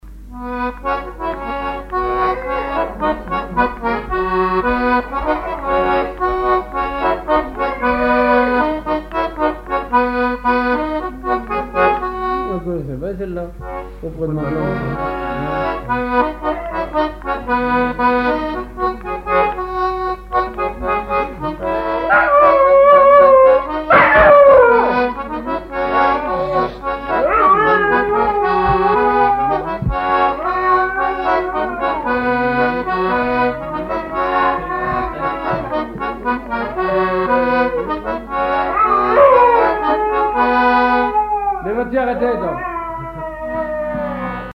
Saint-Hilaire-de-Riez
gestuel : à marcher
accordéon diatonique
Pièce musicale inédite